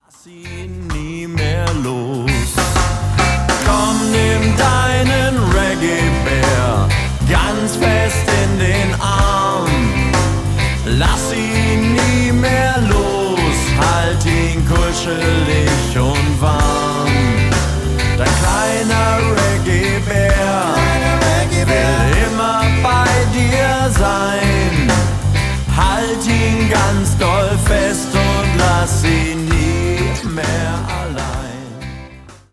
Kindermusik
Ska